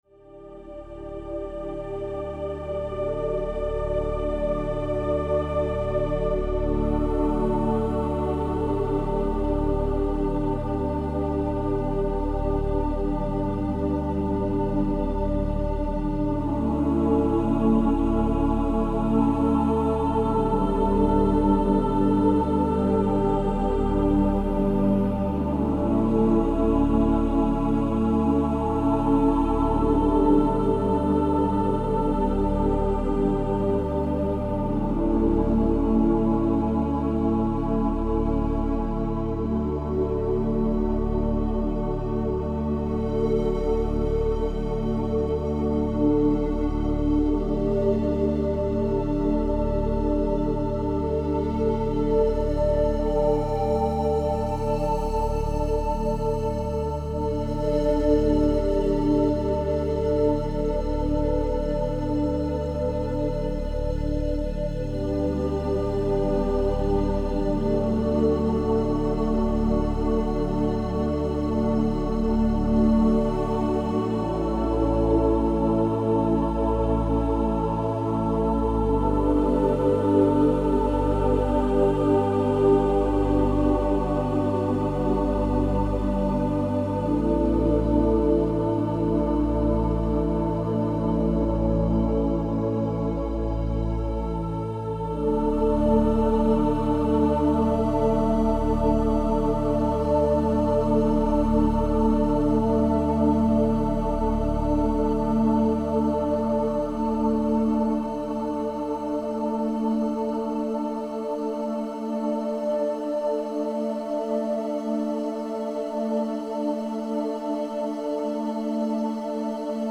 transformational healing music